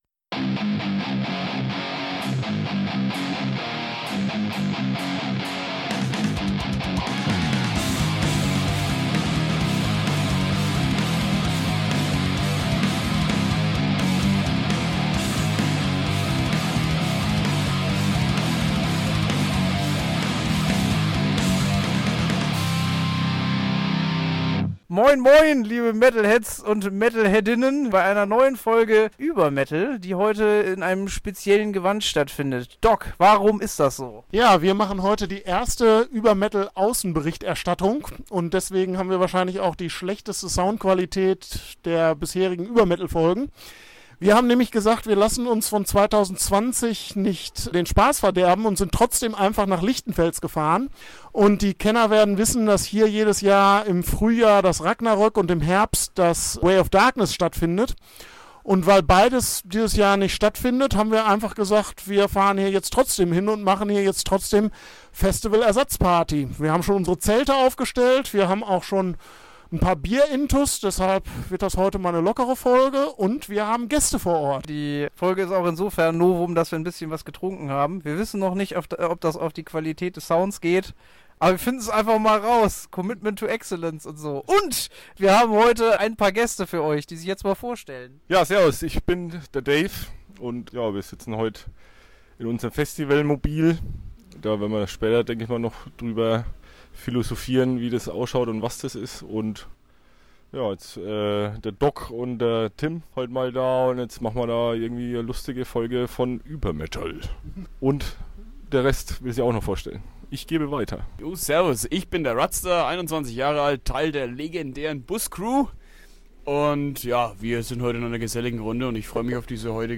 Für die heutige Folge haben wir mal wieder alle möglichen Mühen auf uns genommen und sind für euch ins oberfänkische Lichtenfels gefahren.